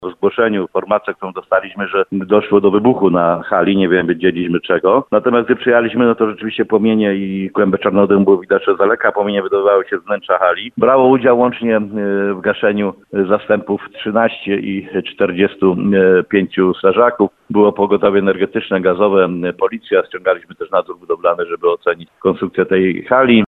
– Dokładne przyczyny pożaru na razie nie są znane – mówi Paweł Motyka, komendant Państwowej Straży Pożarnej w Nowym Sączu.